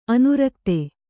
♪ anurakte